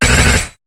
Cri de Terhal dans Pokémon HOME.